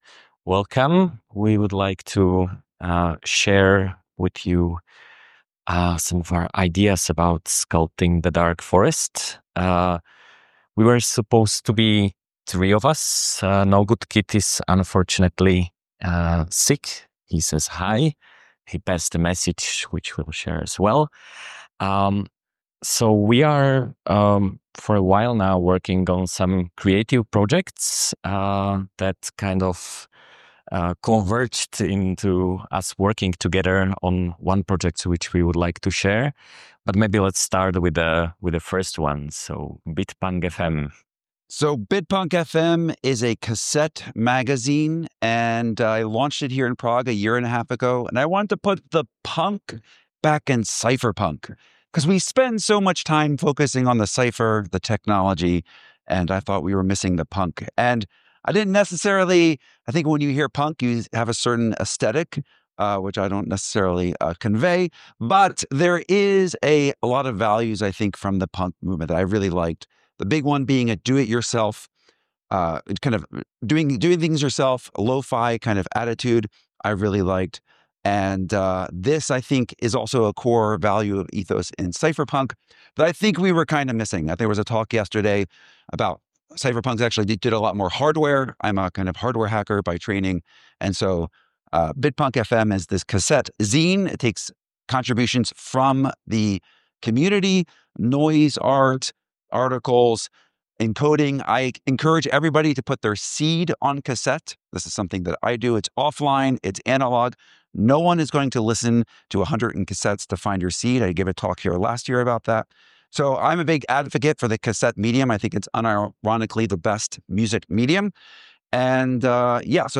Talk about Dark Forest Zin
We have launched the Dark Forest ZIN #0 at Dark Prague conference.